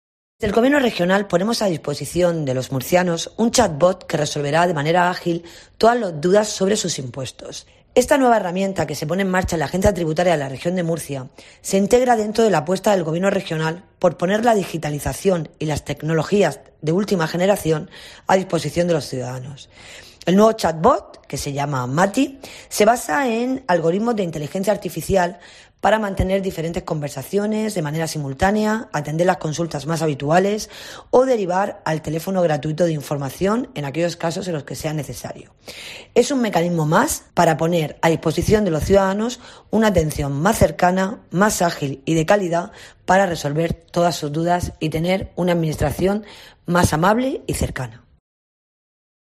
Sonia Carrillo, secretaria general de la Consejería de Economía y Hacienda